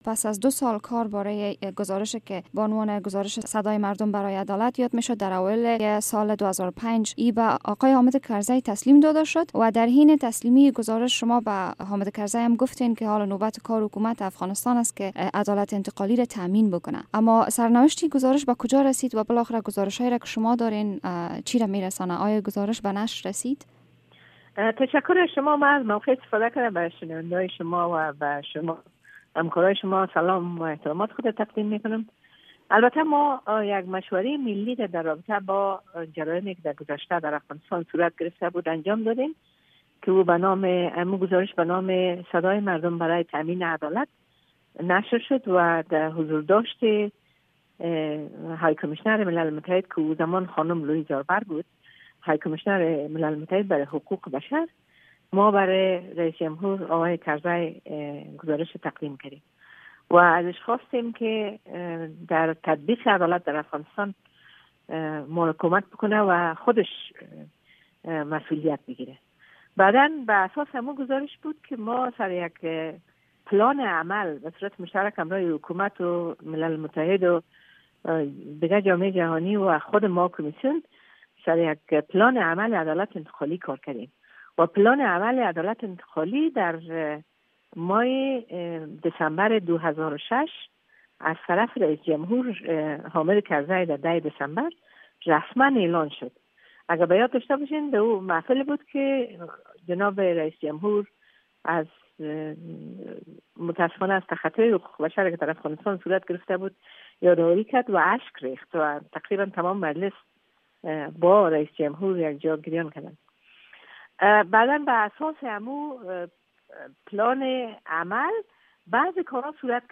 مصاحبه با سیما سمر در باره گزارش جنایات جنگی در افغانستان